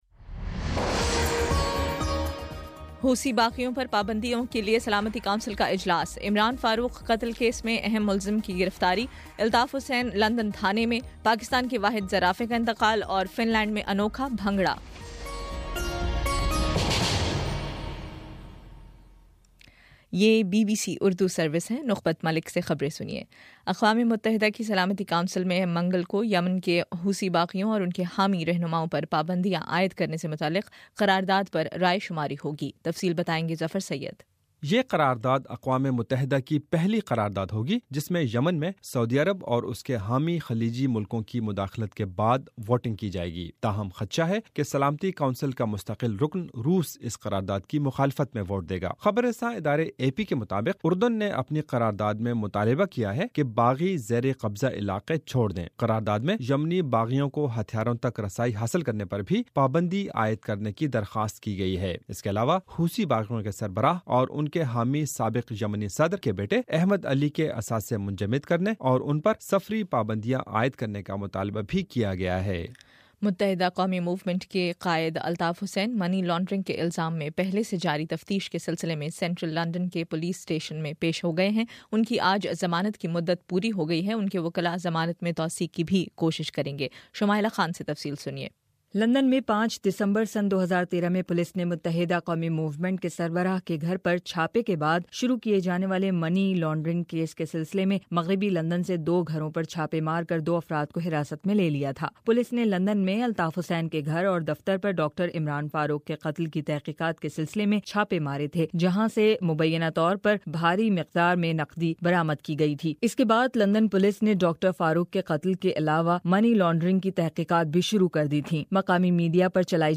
اپریل14: شام پانچ بجے کا نیوز بُلیٹن